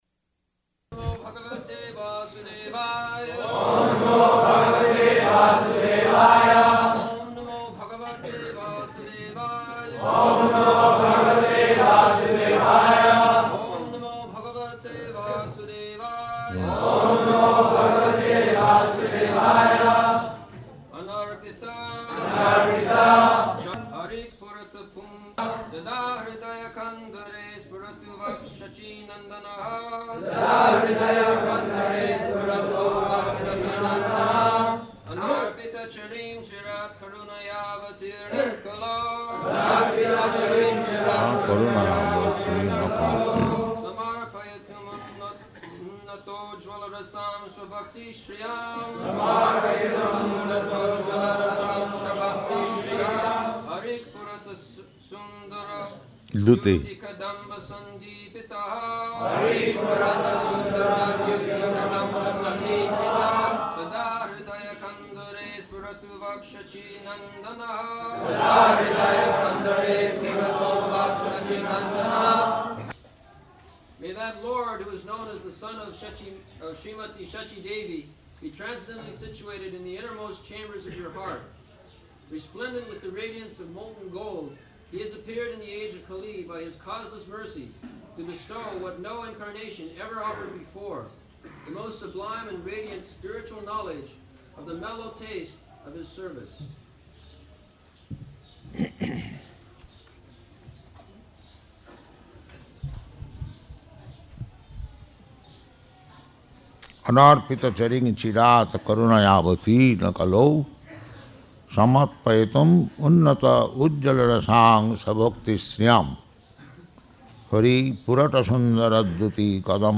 March 28th 1975 Location: Māyāpur Audio file
[Prabhupāda and devotees repeat] [leads chanting of verse, etc.]